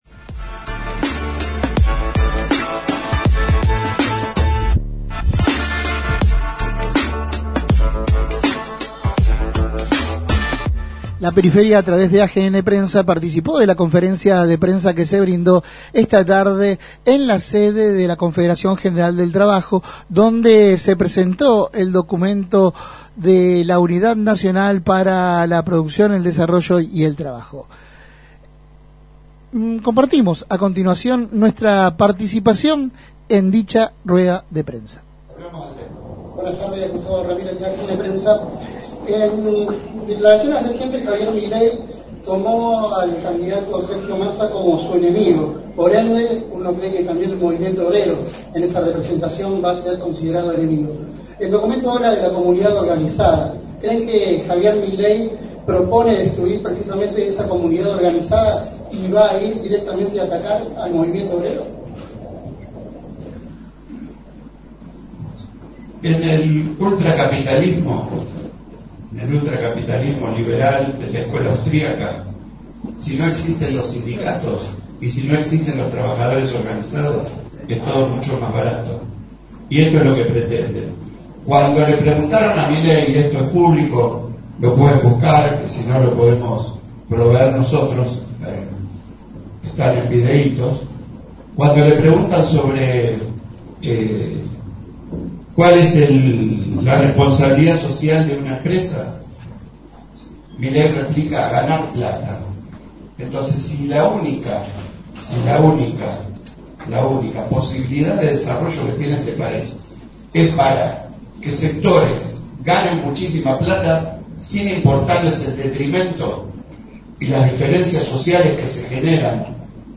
El Co-Secretario General de la CGT, en rueda prensa sostuvo que las propuestas de Javier Milei son antagónicas al postulado de Justicia Social que defiende y asegura el Movimiento Obrero.